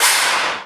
HR16B I-HIT1.wav